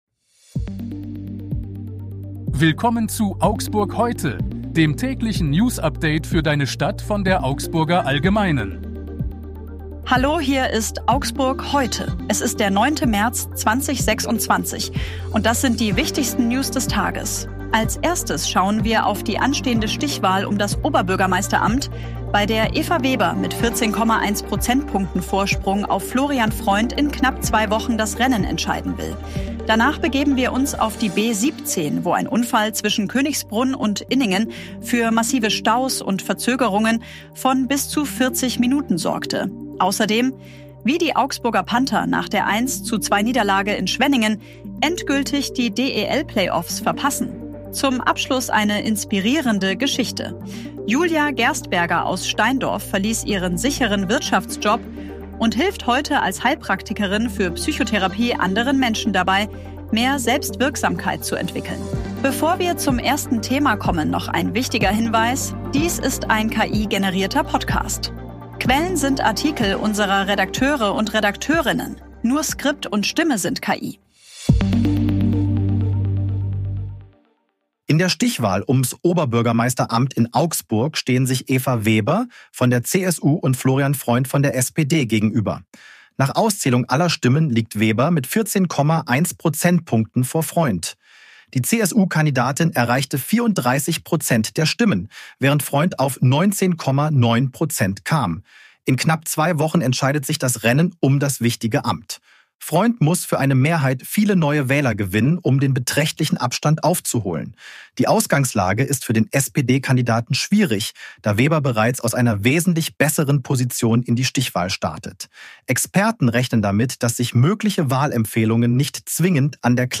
Redakteurinnen. Nur Skript und Stimme sind KI.